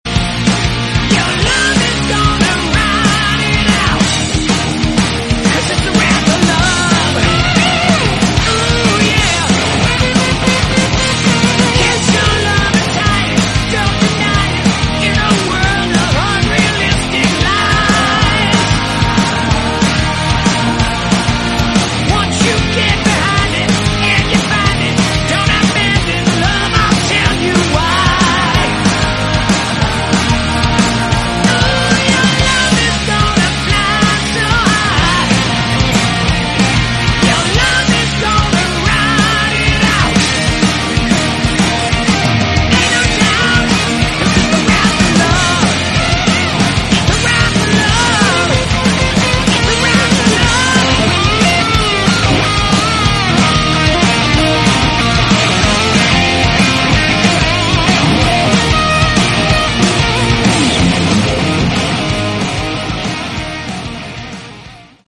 Category: Rock
vocals, guitar, bass
bass guitar